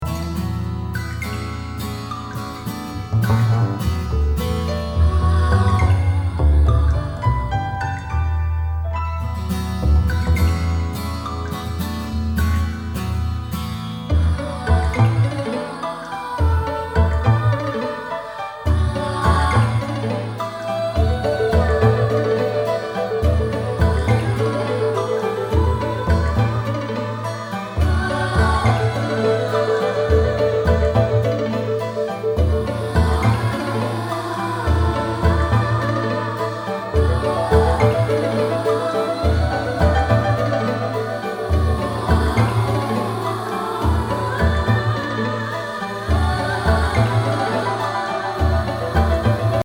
シンセ演奏